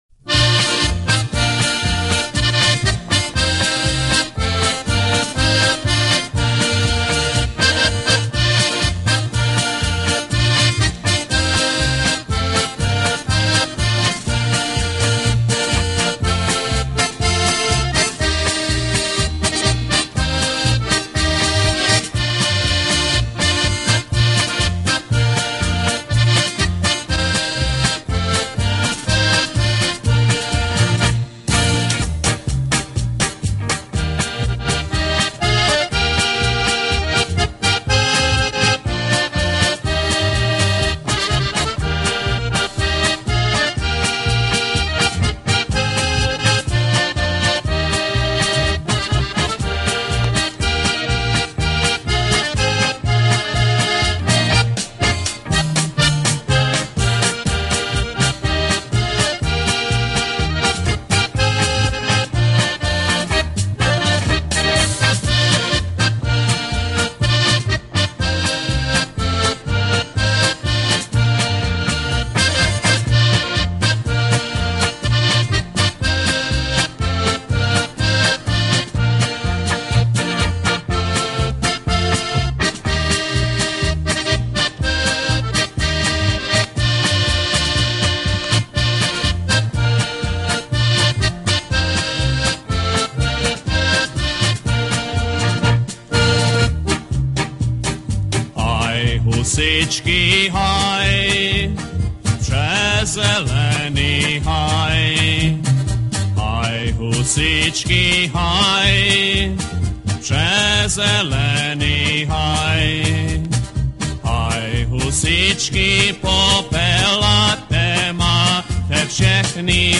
Commentary 8.